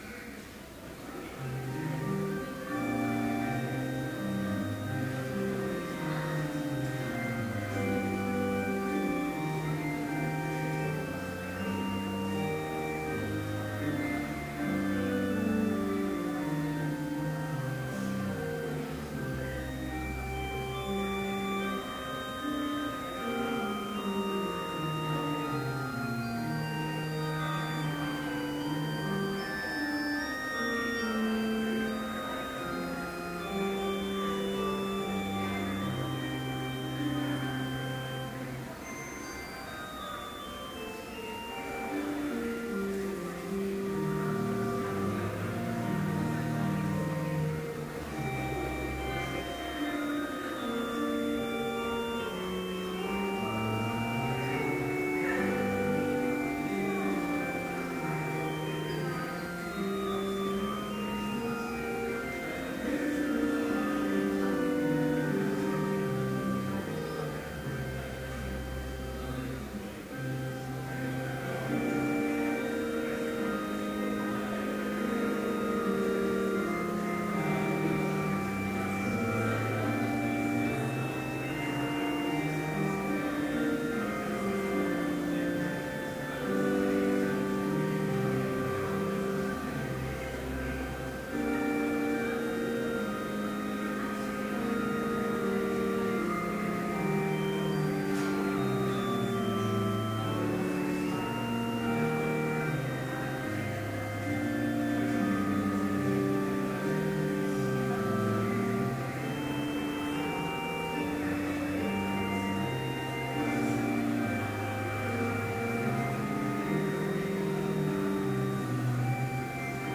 Chapel worship service in BLC's Trinity Chapel
Complete service audio for Chapel - February 6, 2013